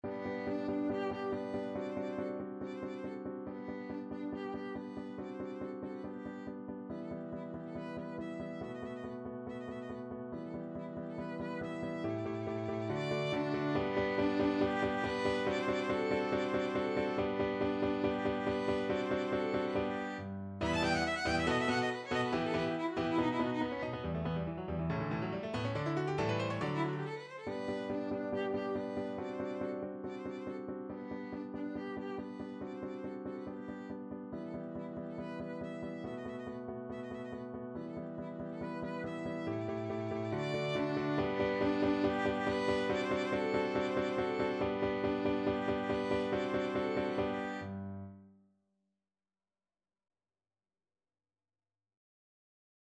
Violin version
~ = 140 Allegro vivace (View more music marked Allegro)
2/4 (View more 2/4 Music)
Classical (View more Classical Violin Music)